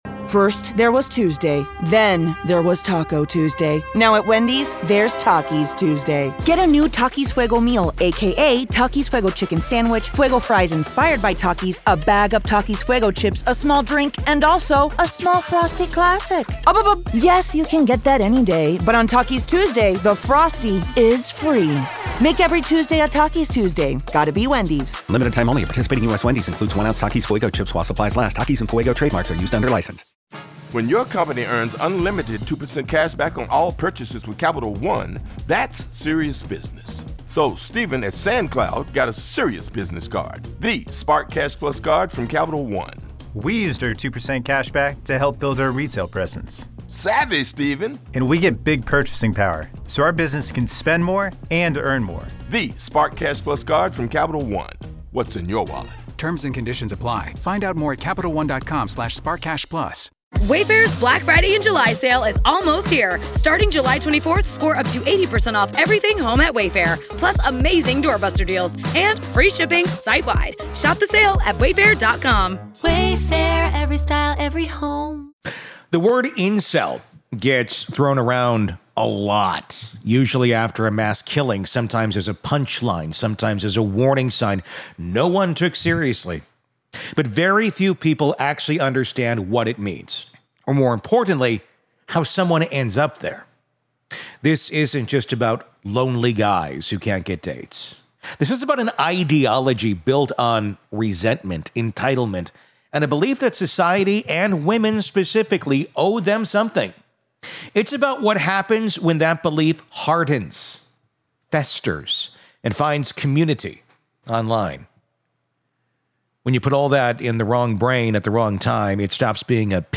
If you've ever wondered how someone gets pulled into this world, or why it keeps happening over and over again, this conversation explains it in human terms.